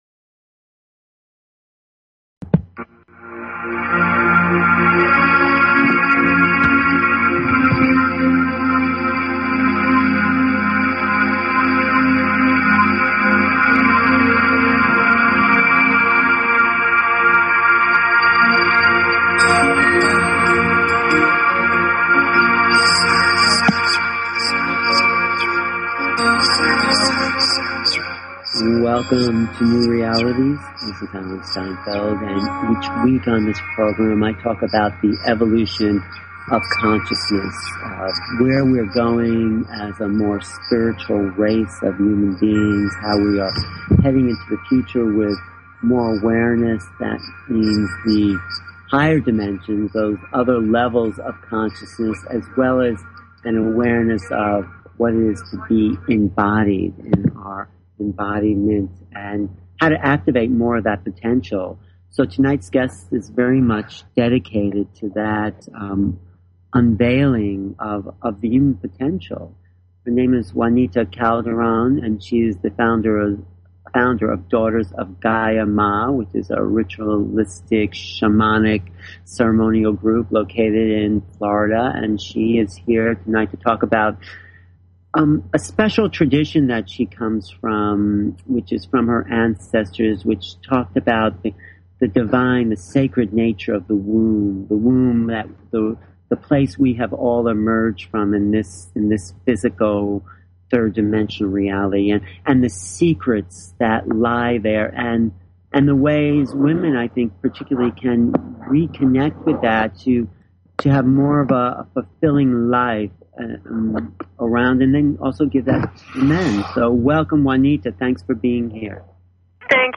New Realities Talk Show